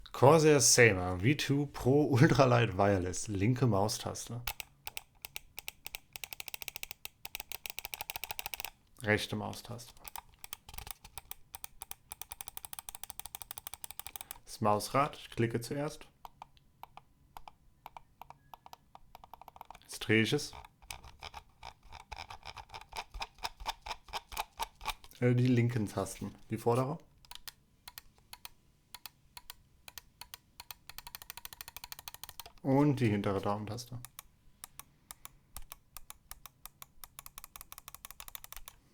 Abseits dieser allgemeinen Kritik kann zusätzlich festgehalten werden, dass die Primärtasten der kabellosen Sabre nur mäßig angenehm klicken.
Positiv anzumerken ist eine vergleichsweise niedrige Lautstärke beim schnellen Drehen.